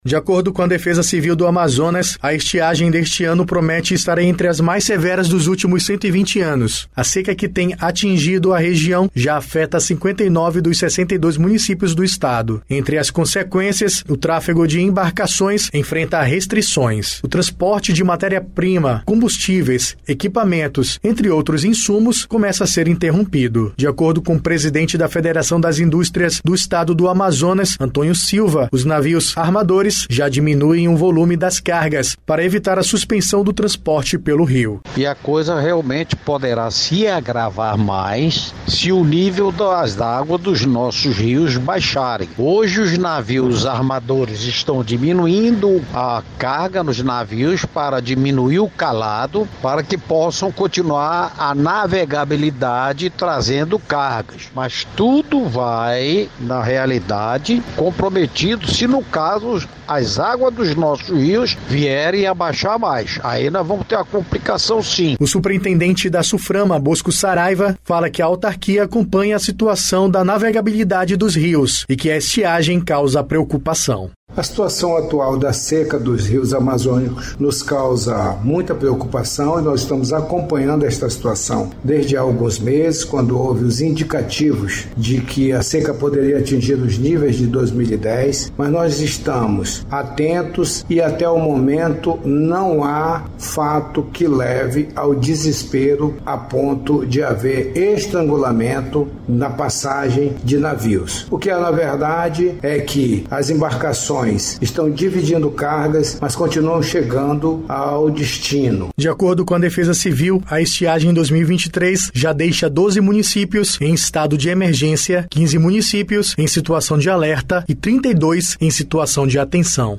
Reportagem
O superientendente da Suframa, Bosco Saraiva, fala que a autarquia acompanha a situação da navegabilidade dos rios e que a estiagem causa preocupação.